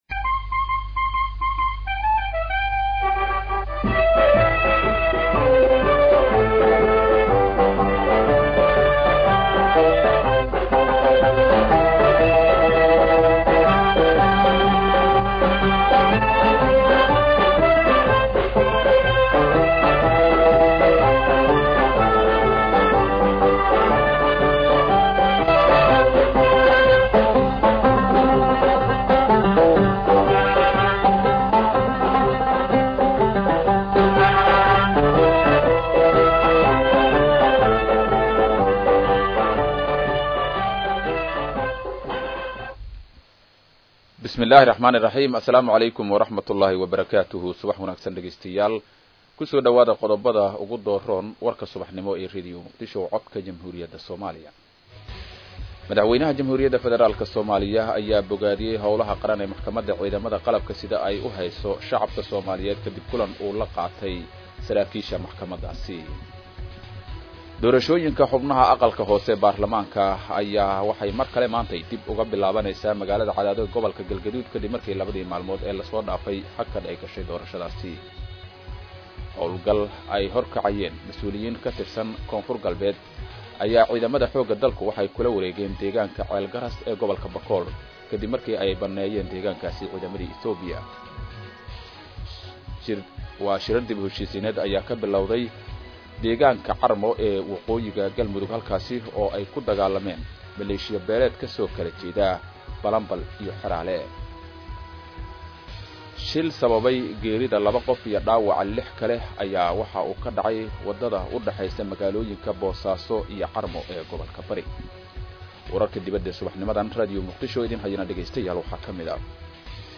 LIVE STREAM KUBIIR ASXAABTEENNA DHAGAYSO WARKA Warka Subaxnimo Warka Duhurnimo Warka Fiidnimo